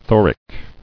[tho·ric]